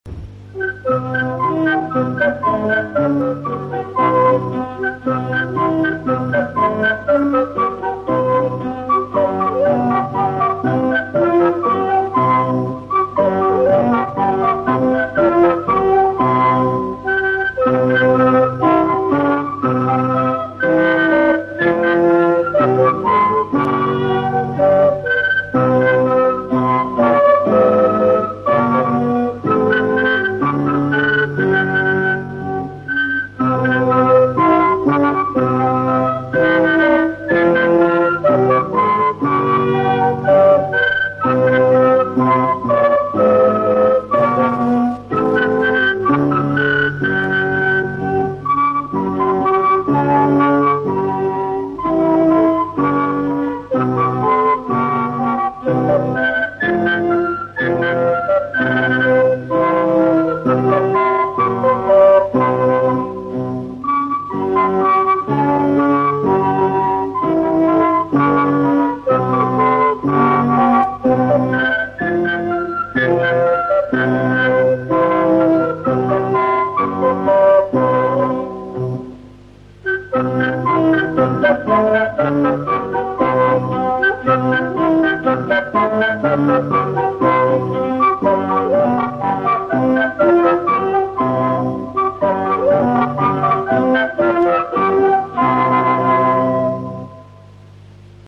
Tylko tyle udało się jak na razie odnaleźć, a ich jakość, delikatnie mówiąc, nie jest najlepsza.
Audycja radiowa. Gawot w wykonaniu fletów, altówki i gitary
03gawot.mp3